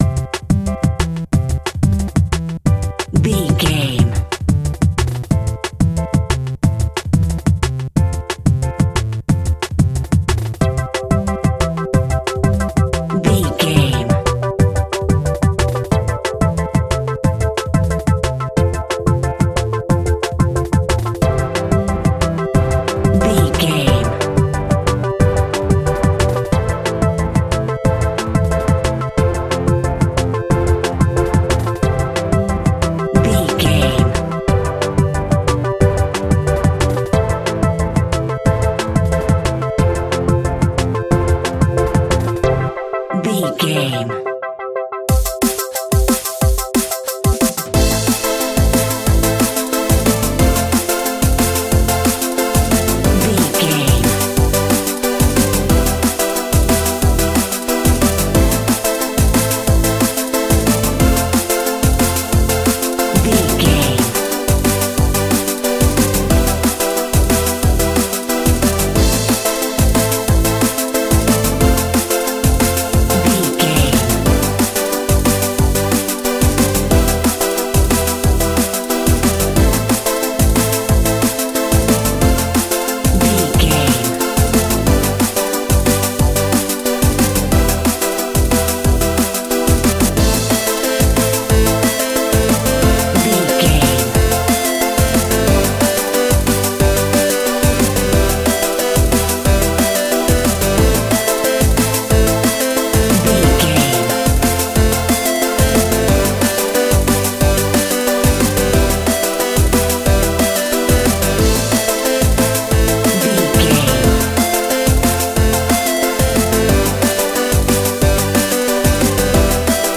Aeolian/Minor
Fast
futuristic
hypnotic
industrial
dreamy
frantic
drum machine
synthesiser
electronic
sub bass
synth leads